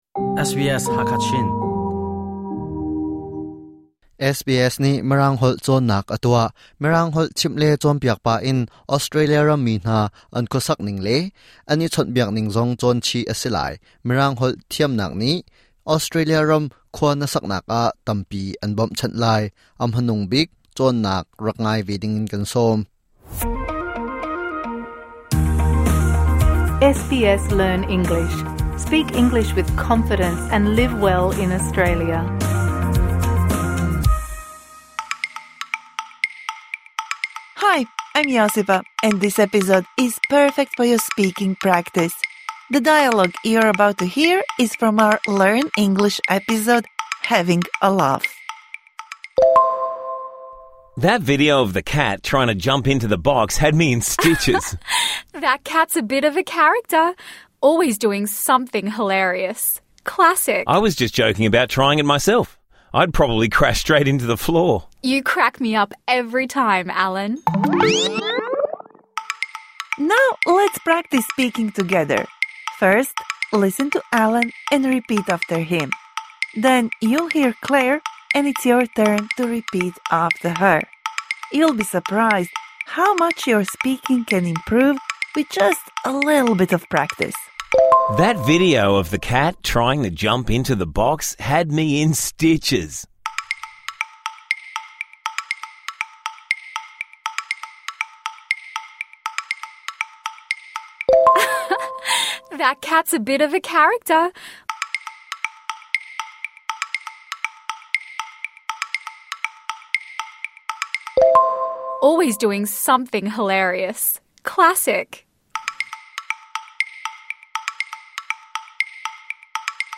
This bonus episode provides interactive speaking practice for the words and phrases you learnt in #101 Having a laugh (Med)